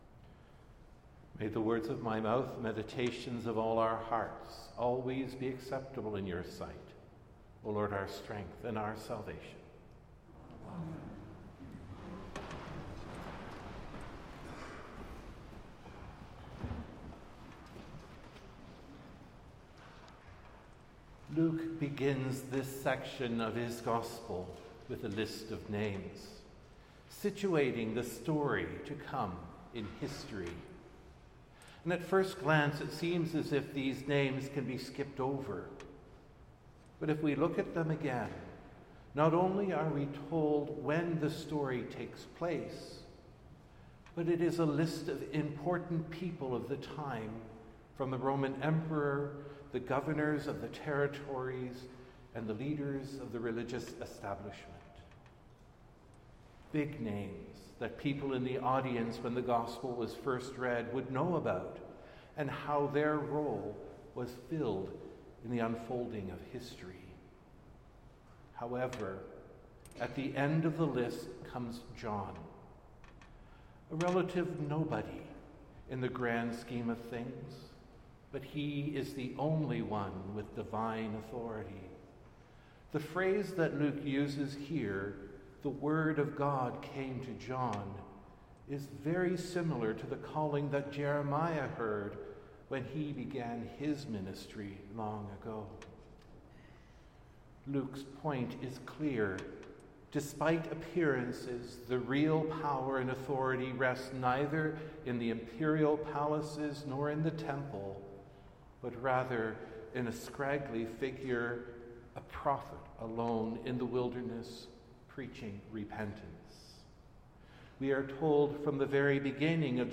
Sermons | Christ Church Cathedral Ottawa